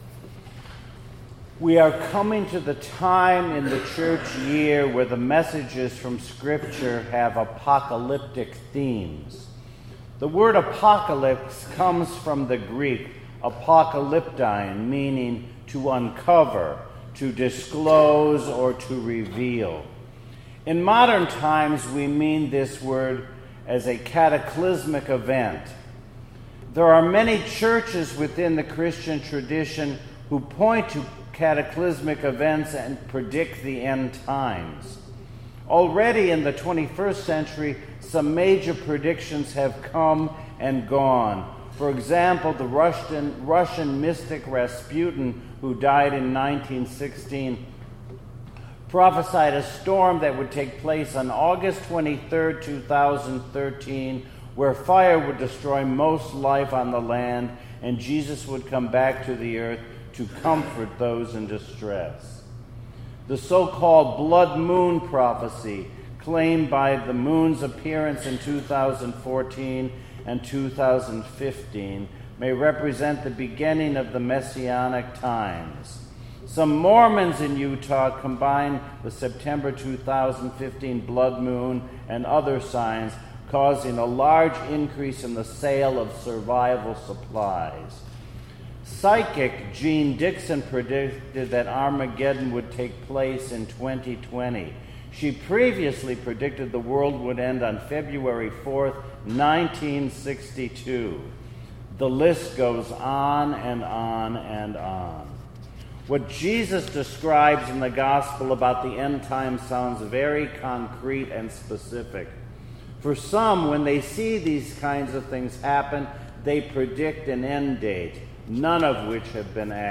2018 Sunday Sermon November 18